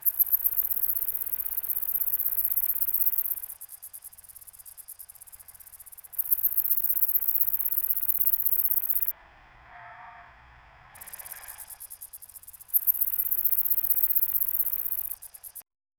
Cinematic sounds of peaceful wildlife enimal life friendship realistic evoke emotions, capturing the essence of a serene natural environment.
cinematic-sounds-of-peace-kf4c6imh.wav